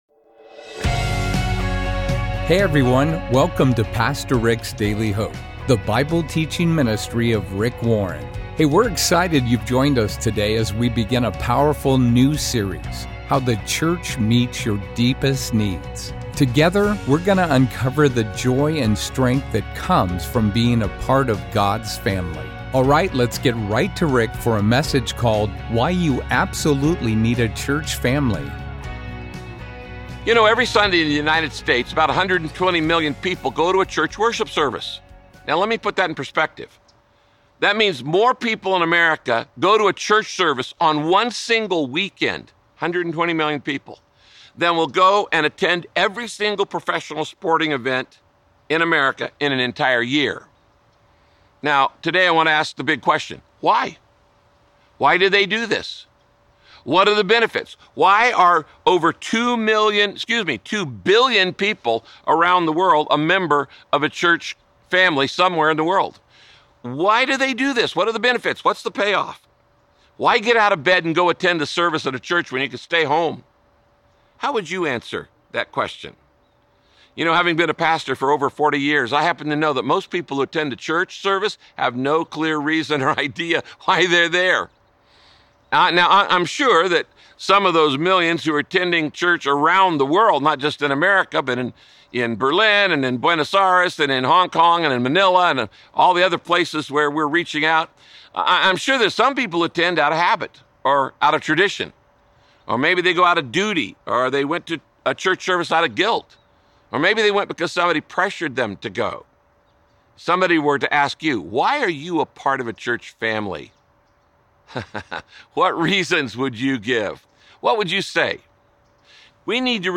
In this broadcast, Pastor Rick examines why the churc…